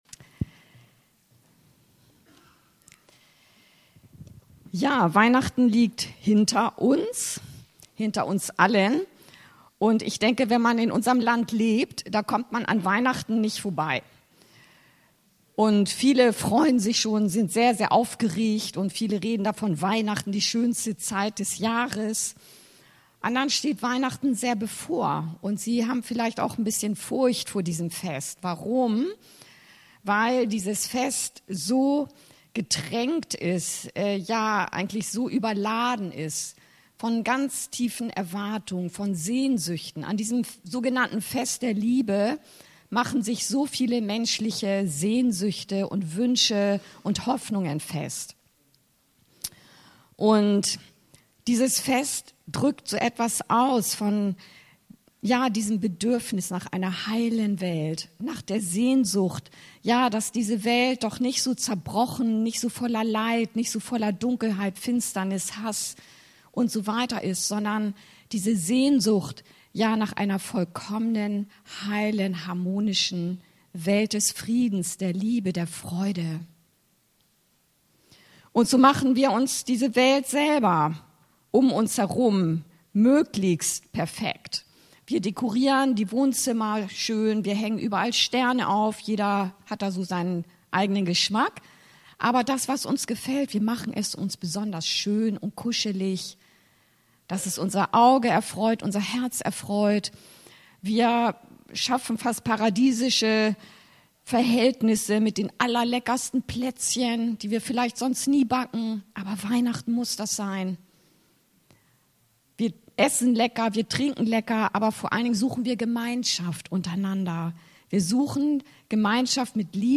Das größte Weihnachtsgeschenk aller Zeiten! ~ Anskar-Kirche Hamburg- Predigten Podcast
Weihnachten ist vorbei, aber was bleibt, ist das Geschenk, das Gott selbst uns für alle Zeiten mit Jesus geschenkt hat! Dieses Geschenk wollen wir in der Predigt zusammen auspacken und uns neu begeistern lassen.